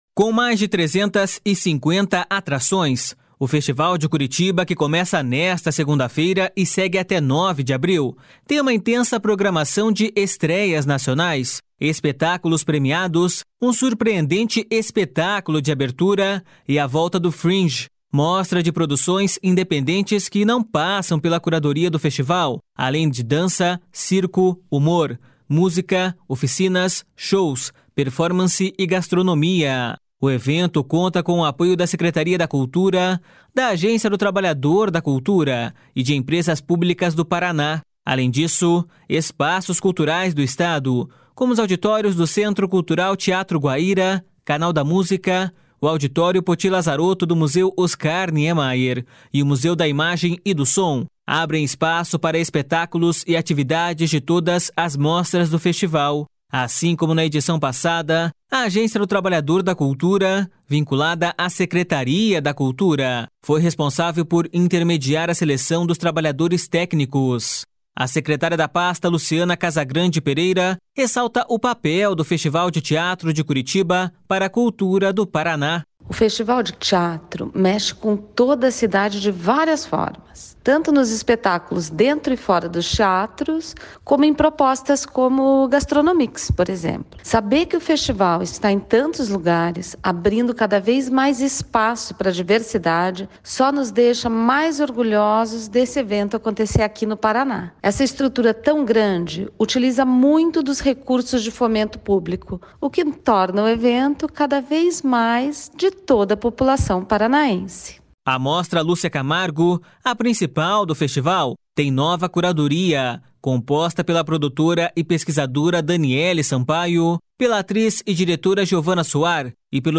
A secretária da pasta, Luciana Casagrande Pereira, ressalta o papel do Festival de Teatro de Curitiba para a cultura do Paraná.// SONORA LUCIANA CASAGRANDE PEREIRA.//